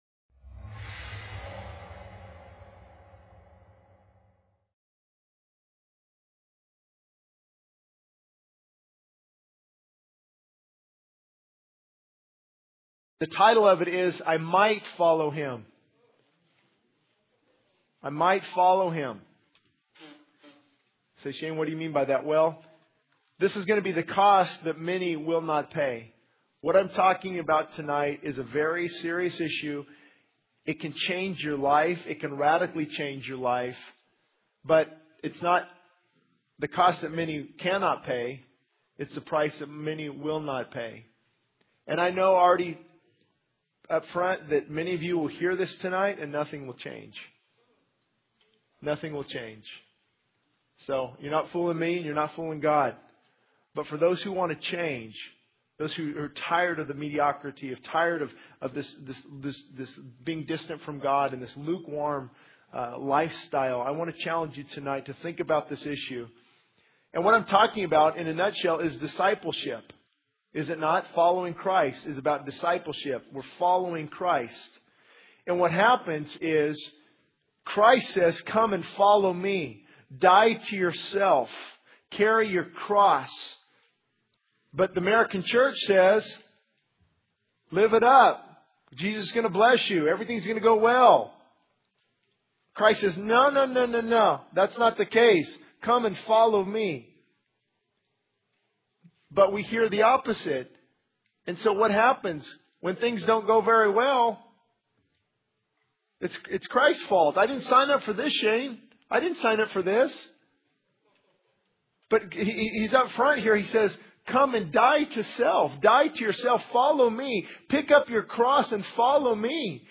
The sermon emphasizes the importance of true discipleship, challenging believers to die to self, carry their cross, and follow Christ unconditionally. It highlights the need for a baptism of love, urging individuals to return to their first love for God and to prioritize loving one another. The speaker addresses the dangers of drifting from God's love, the perils of hypocrisy versus struggling in faith, and the critical choice between the way of life and the way of death.